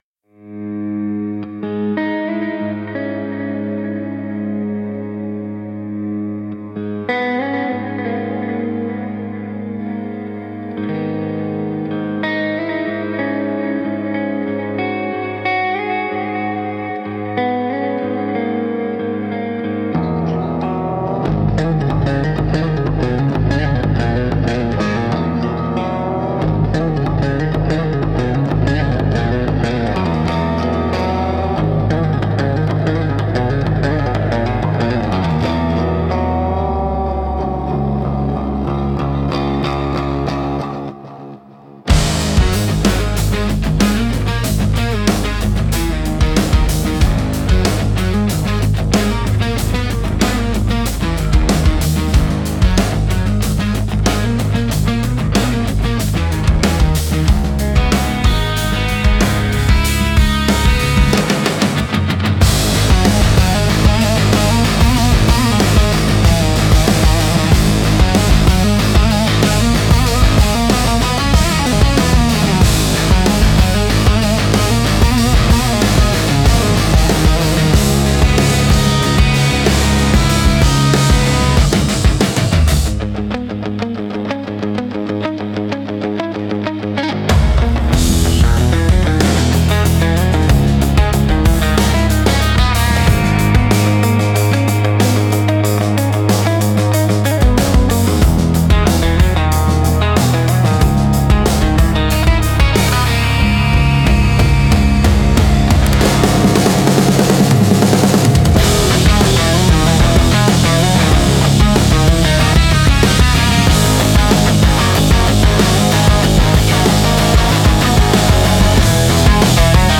Instrumental - Buried Harmonics 4.13